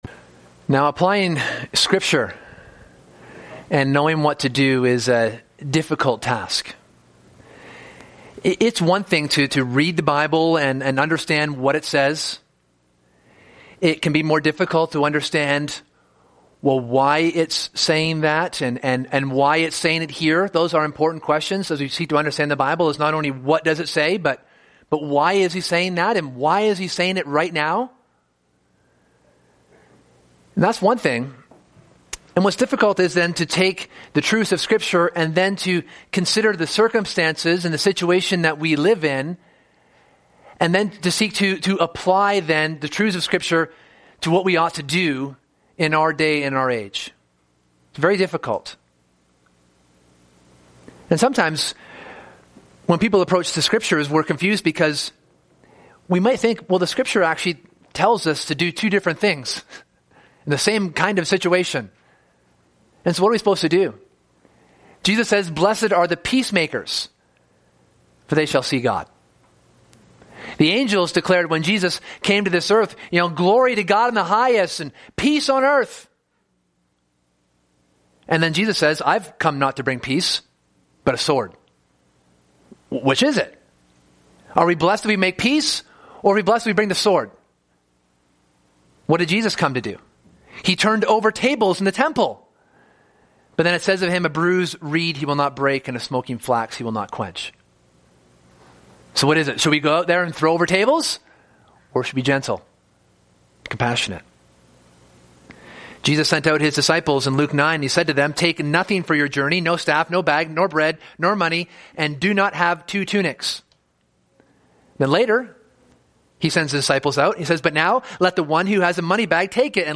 In this sermon we examine 1 Peter 2 and living as Christians under authority in a fight for the gospel. When do we rise up and when do we lay low?